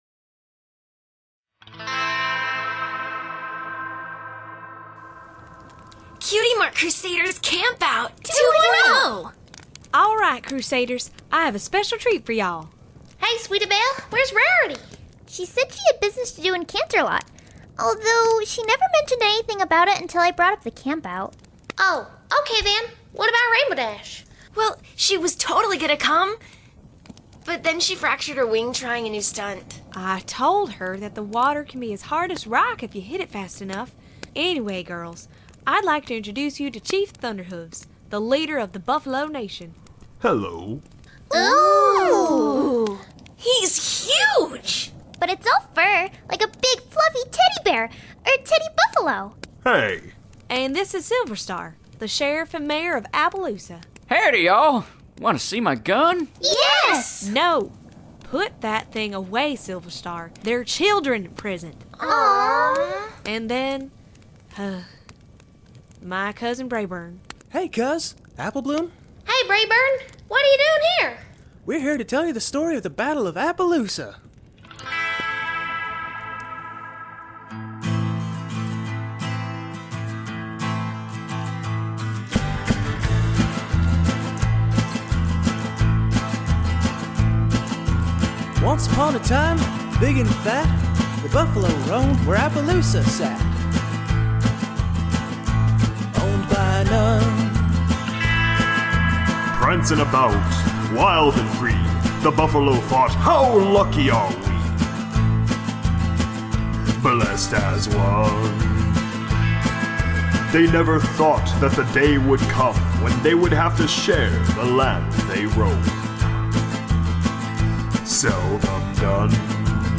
Also hope you can tolerate my awful Braeburn voice.